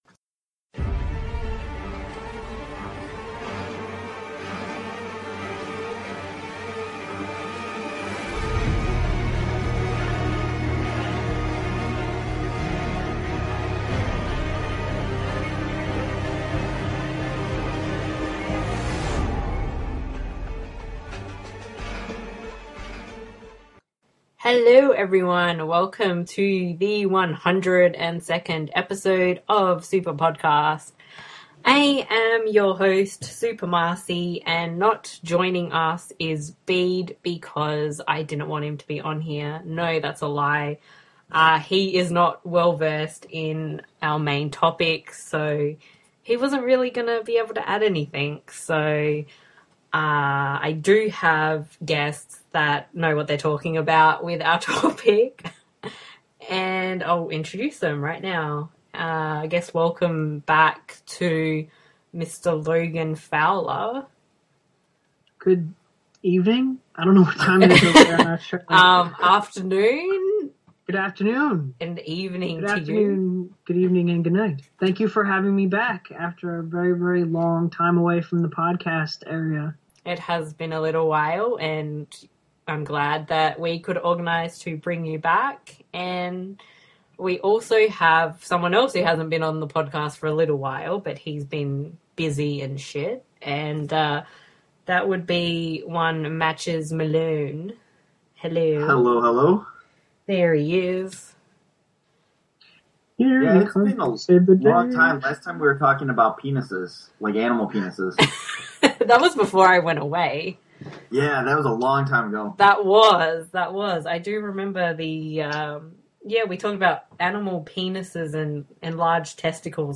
Enter the Asylum Doors to The Walking Dead As is tradition now, we have a mid-season discussion on The Walking Dead.